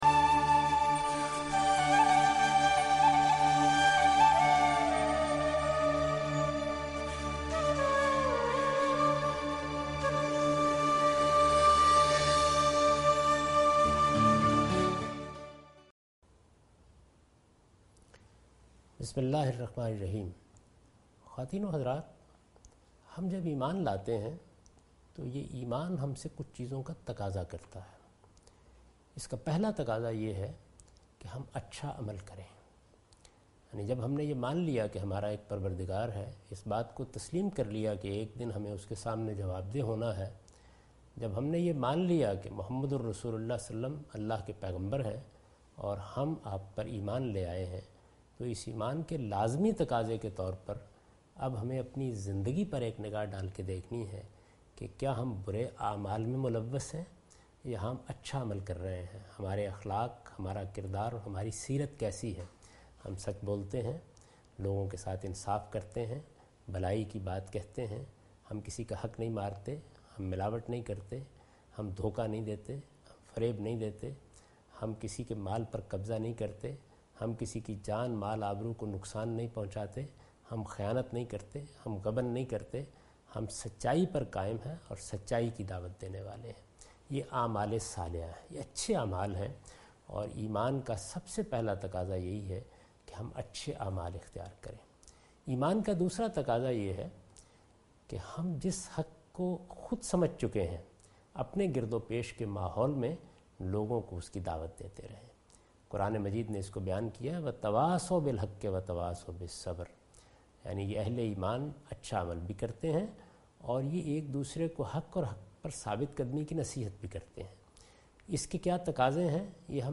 his series contains the lecture of Javed Ahmed Ghamidi delivered in Ramzan.